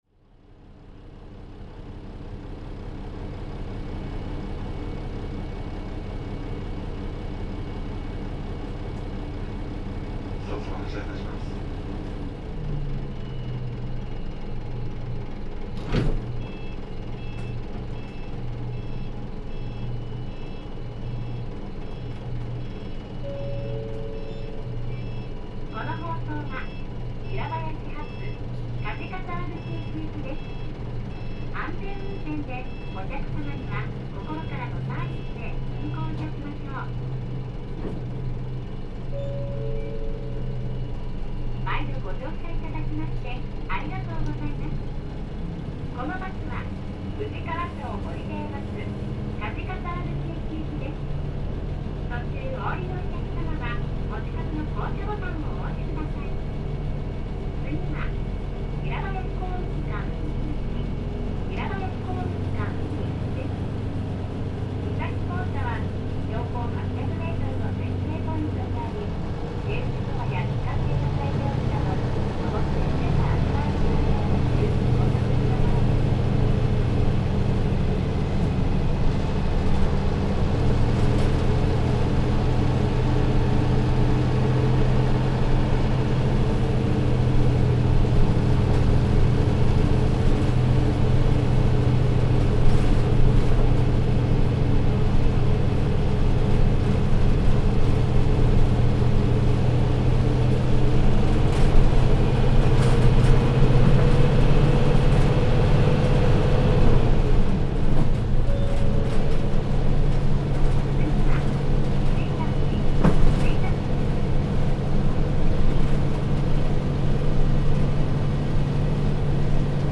山梨交通・山交タウンコーチ いすゞ P-LV314K ・ 走行音(全区間)(その1) (36.3MB★) 収録区間：富士川町ホリデーバス 小室線 鰍沢口駅→小室 ・ 走行音(全区間)(その2) (38.6MB★) 収録区間：富士川町ホリデーバス 平林線 鰍沢口駅→平林 ・ 走行音(全区間)(その3) (40.0MB★) 収録区間：富士川町ホリデーバス 平林線 平林→鰍沢口駅 自社発注のP代キュービック。
前後扉でロッドシフトとなっており、いかにもひと昔前のバスといった感じの渋く味のあるガラガラとしたエンジン音が特徴的。収録の平林線の平林寄りは平林へ向けひたすら上り勾配で、轟音を上げて1速も使いゆっくりと登っていく。